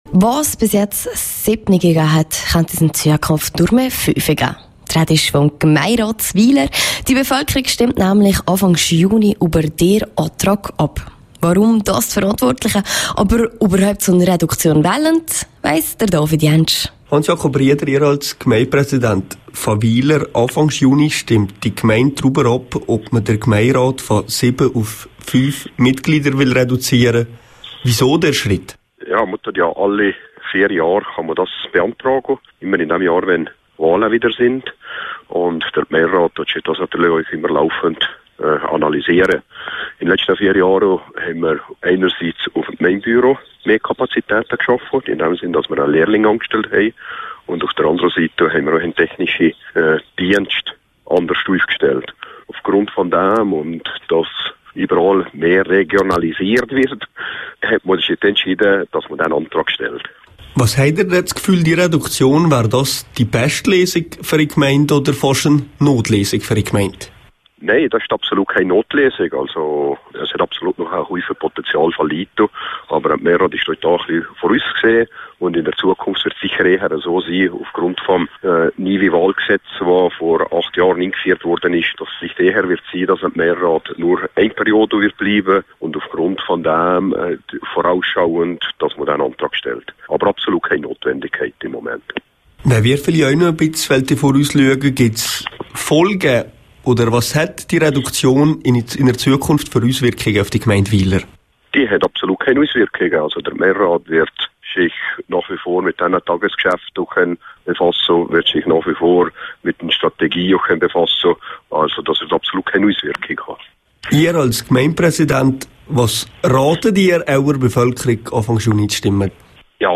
Gemeindepräsident Hans-Jakob Rieder über die Reduktion des Gemeinderates in Wiler.